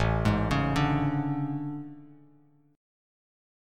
AM11 Chord